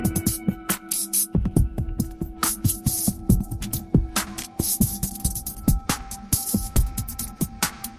generate a rap song